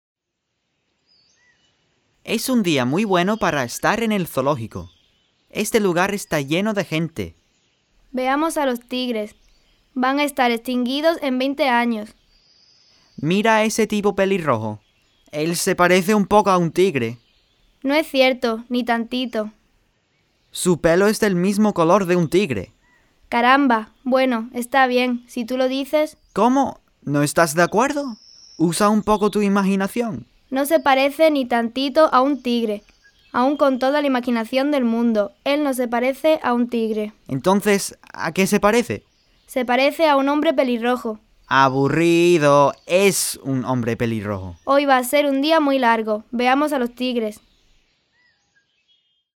Whimsical Dialogues for Upper Level Classes
The Spanish enactment (mp3) can be played while the students read along.